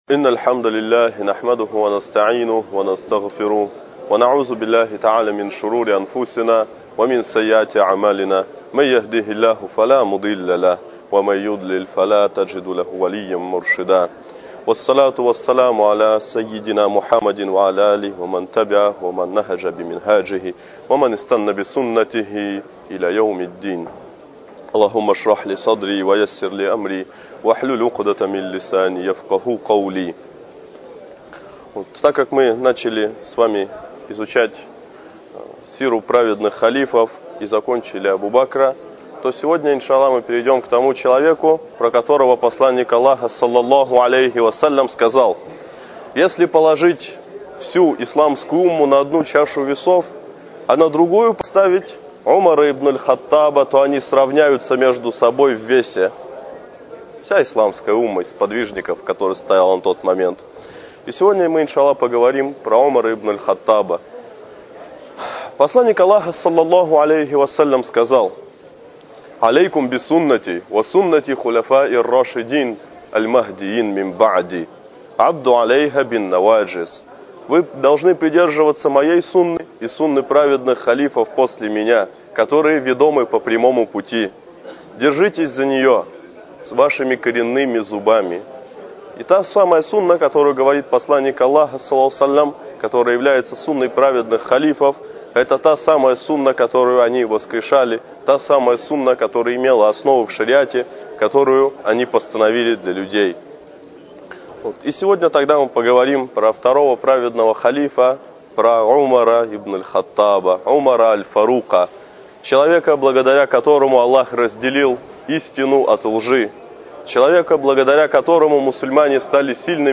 Лекции о праведных предках. Эта лекция о праведном Халифе Умаре ибн Аль-Хаттабе, да будет доволен им Аллах.